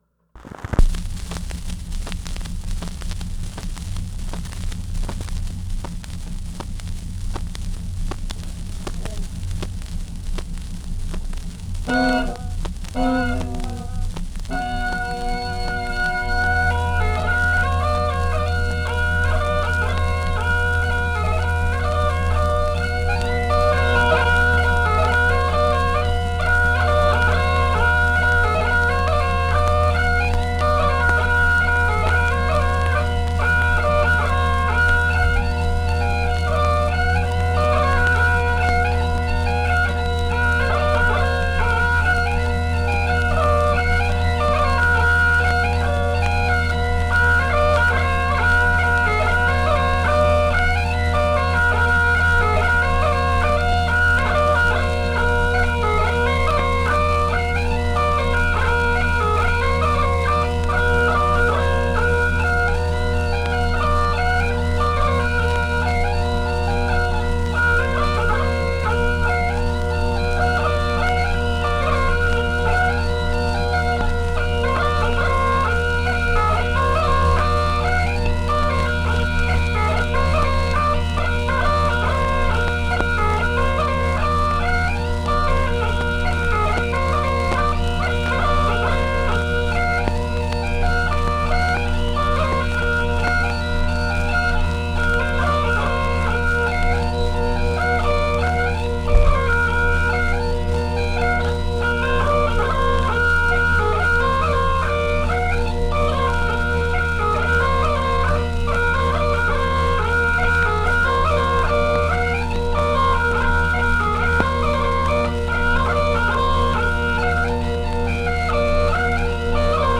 Audiodisc of Some Guy Playing the Bagpipes
I mean, there's probably a difference between a wicked bagpipe player and a shit bagpipe player, but it all sounds like three ducks caught half way in a meat grinder.
Take the three ducks stuck halfway in a meat grinder, and throw the whole meat grinder into a car crusher, and you have the ending.
audiodiscbagpipes.mp3